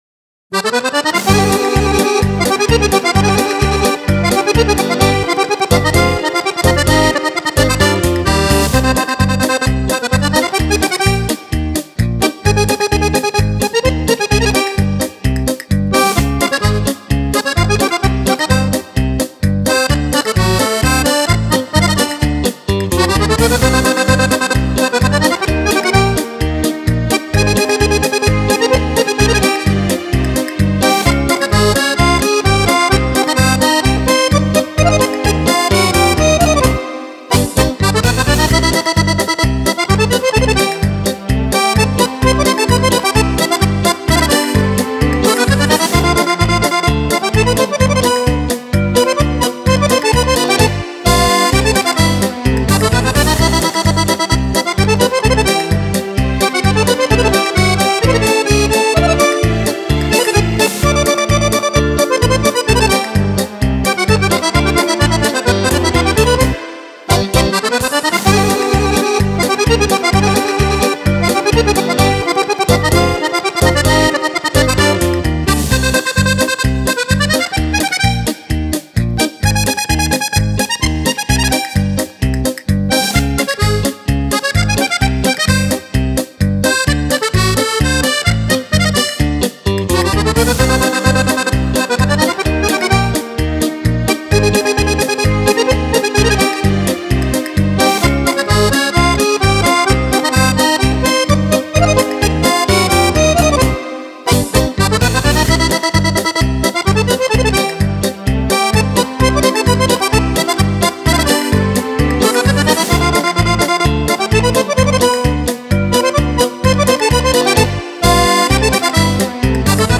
Paso-doble
10 ballabili per Fisarmonica
Fisarmonica
Chitarre